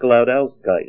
Name Pronunciation: Sklodowskite + Pronunciation Synonym: Chinkolobwite ICSD 1148 PDF 29-875 Sklodowskite Image Images: Sklodowskite Comments: Yellow cotton-ball spray of acicular sklodowskite.